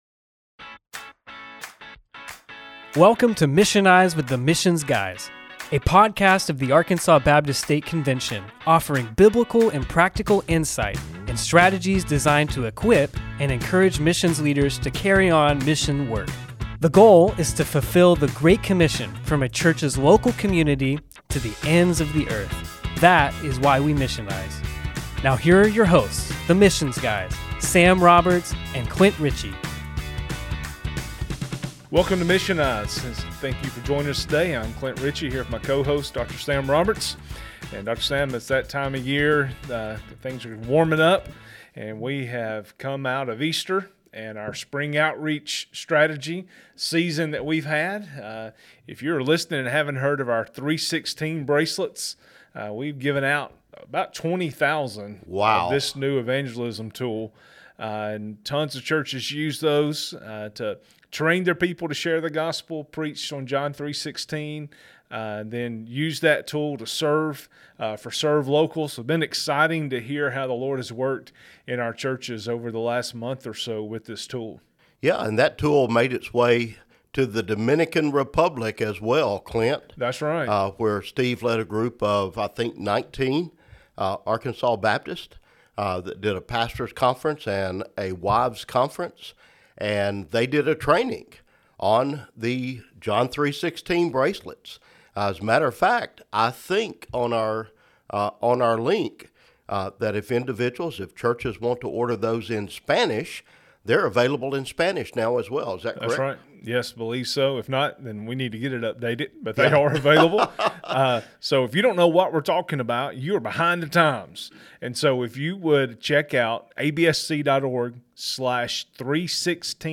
Missionize with the Missions Guys From Curriculum to Relationship - a Conversation